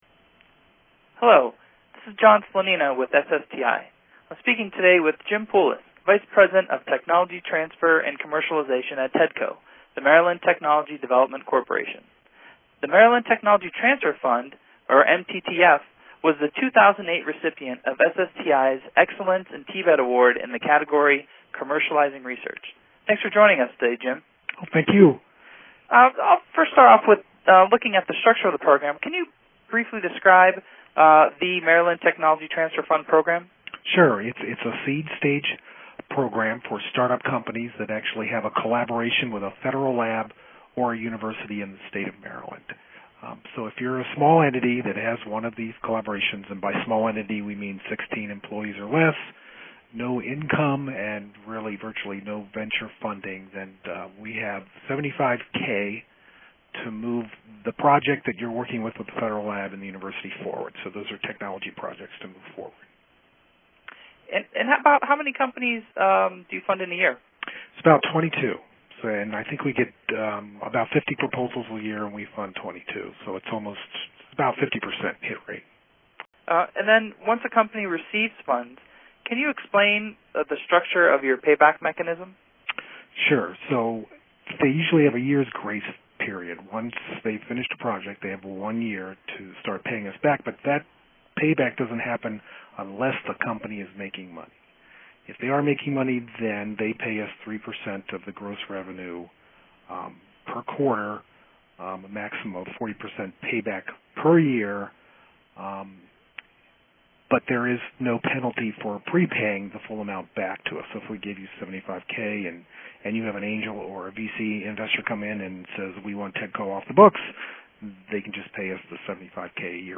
four-and-a-half-minute condensed version of the interview or the full conversation (10 minutes).